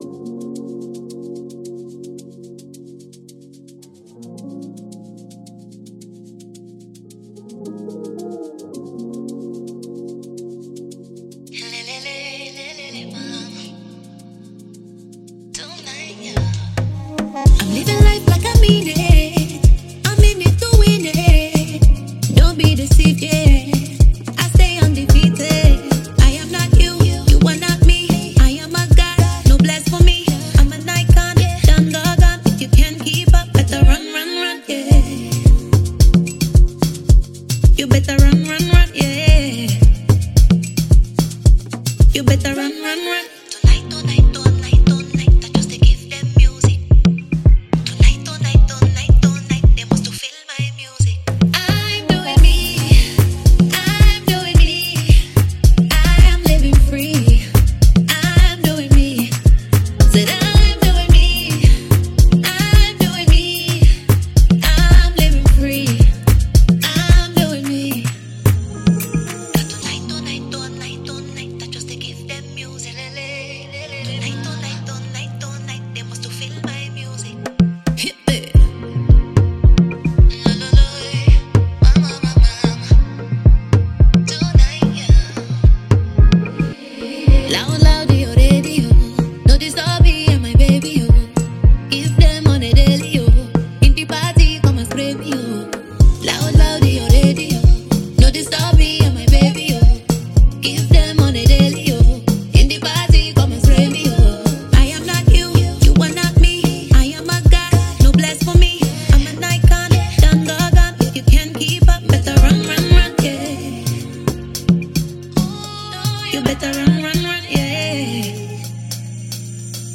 Impressive Nigerian female singer and songwriter